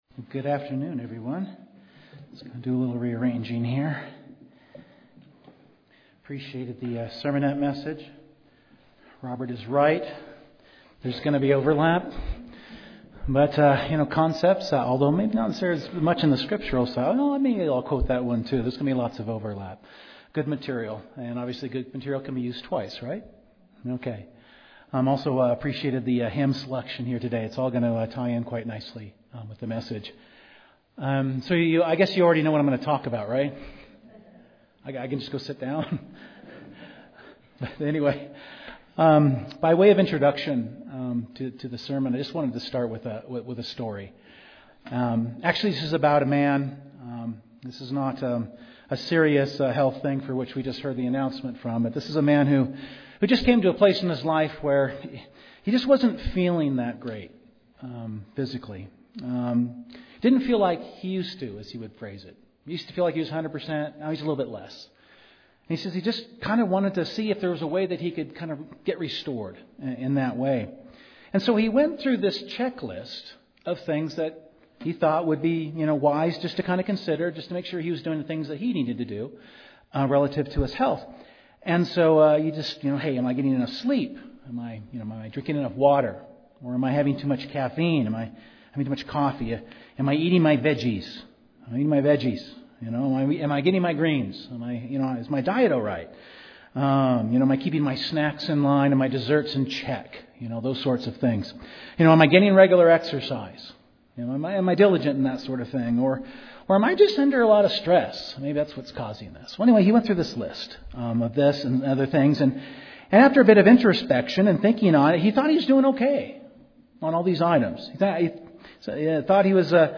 The teachings of the bible include the importance of self-examination (2 Cor. 13:5). This sermon focuses on some core aspects of effective self-examination; including preparation for the Passover (1 Cor. 11:27-28).